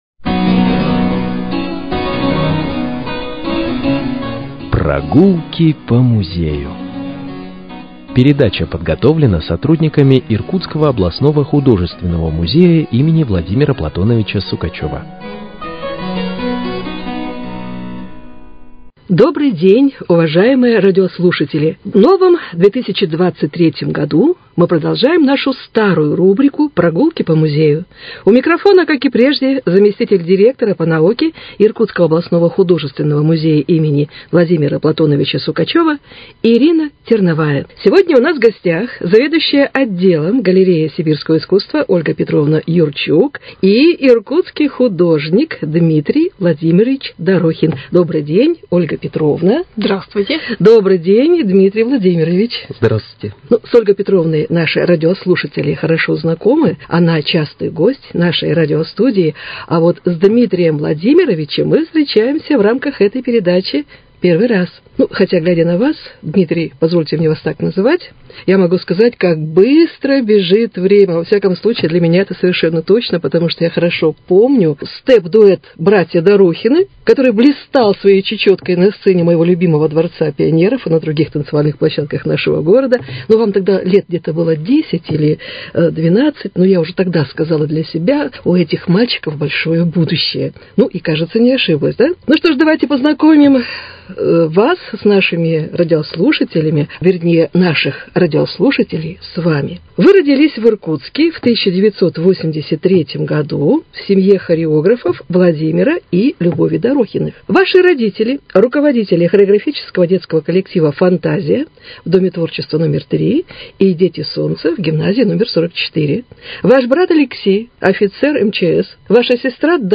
Передача из авторского цикла